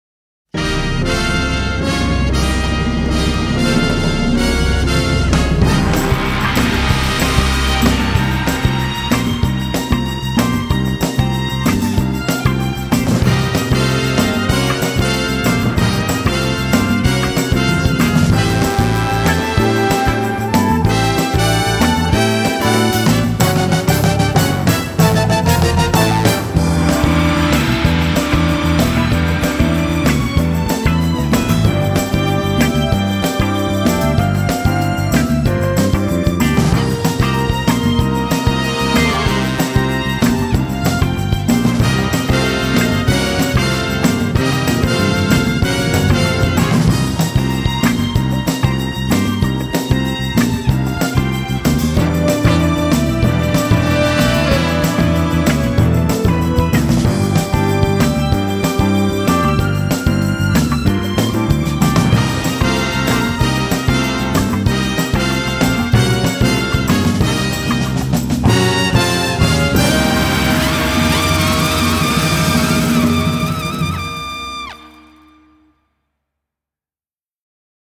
Sigla della serie